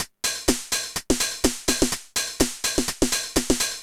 Index of /musicradar/retro-house-samples/Drum Loops
Beat 10 No Kick (125BPM).wav